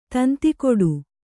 ♪ tanti koḍu